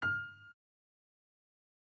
CHOMPStation2/sound/piano/E#7.ogg